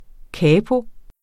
Udtale [ ˈkæːpo ]